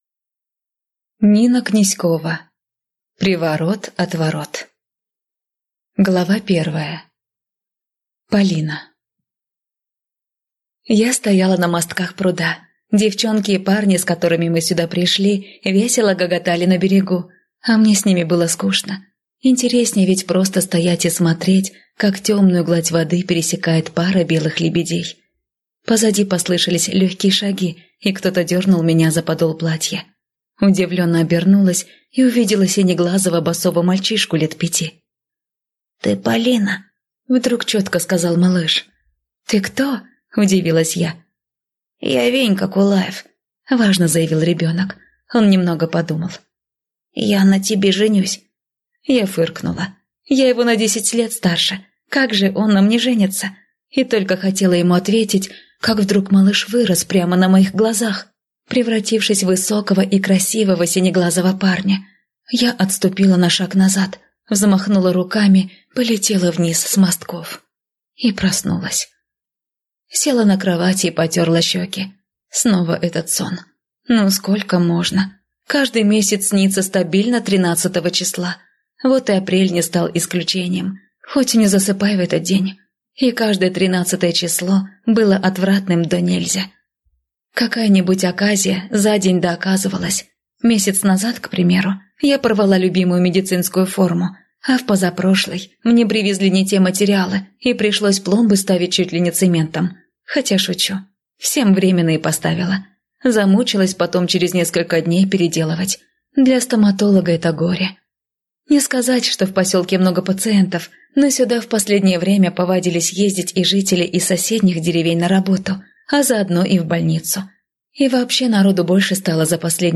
Аудиокнига Приворот от ворот | Библиотека аудиокниг